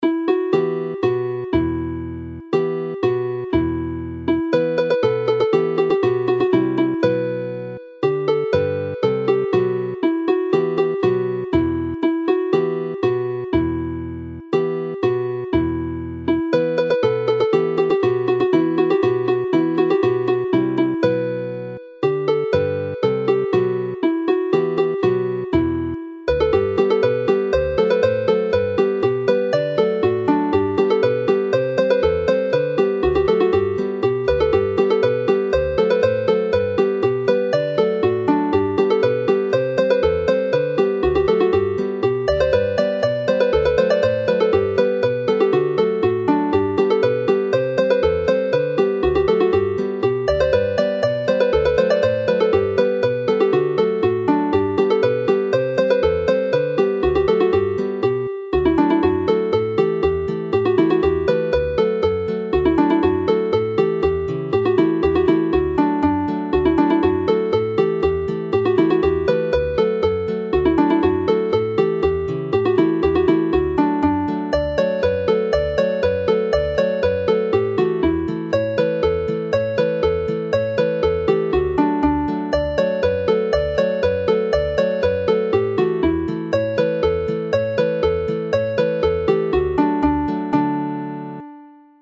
The tunes in this set have been chosen and pitched so that every note is playable on pipes which are generally limited to a single octave, eg. pibgorn, bagpipe.
Pant Corlan yr Ŵyn (valley of the sheep fold) is a popular dance tune pitched in G, so the seventh note in the scale of the D pipe needs to be flattened by covering a suitable combination of the lower holes.
Y Delyn Newydd (the new harp) when pitched in D rather than the usual G fits nicely into the range of the pipe and also works well on other instruments.